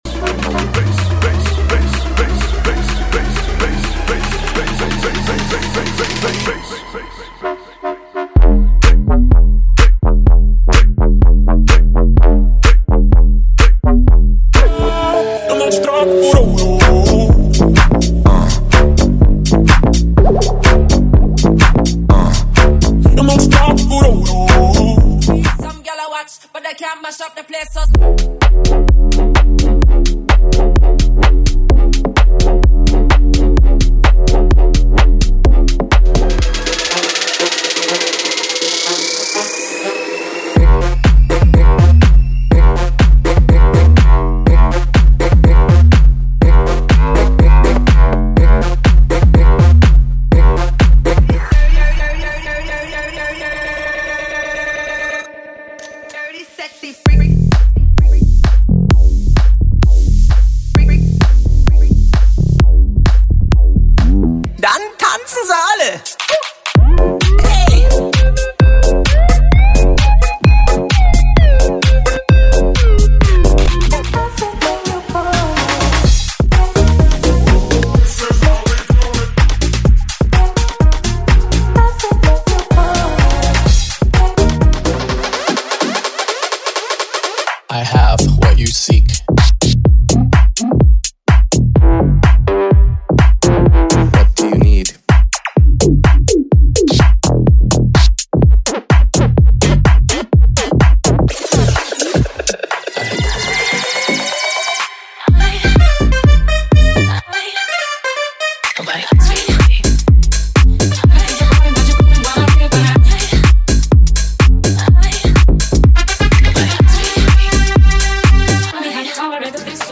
• Desande, Low Bass, Bass House = 100 Músicas
• Versões Extended
• Sem Vinhetas